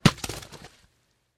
Звуки арбуза
Арбуз - Альтернативный вариант